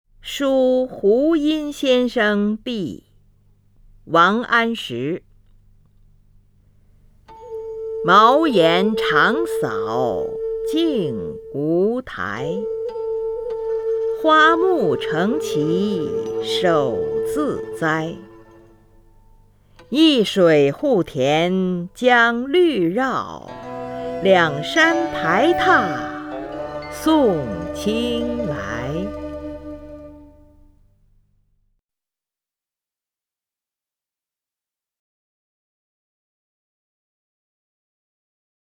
林如朗诵：《书湖阴先生壁》(（北宋）王安石) （北宋）王安石 名家朗诵欣赏林如 语文PLUS